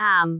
speech
syllable
pronunciation
aam4.wav